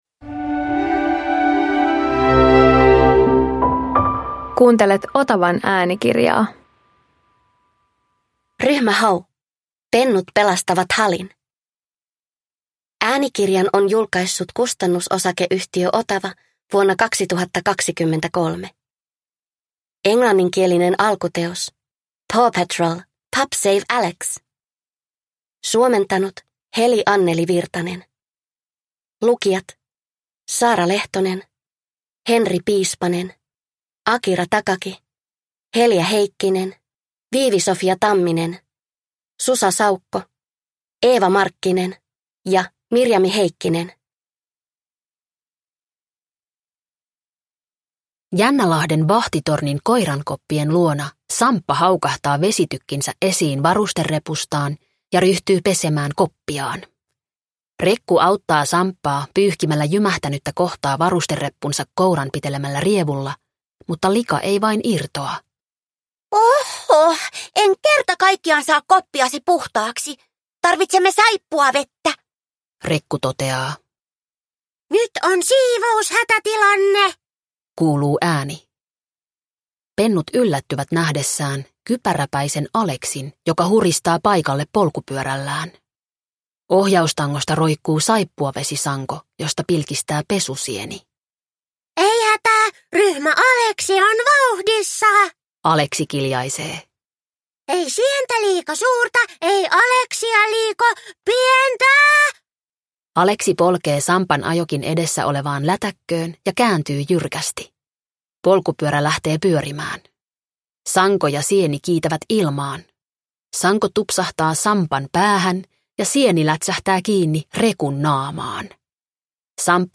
Ryhmä Hau - Pennut pelastavat Halin – Ljudbok
• Ljudbok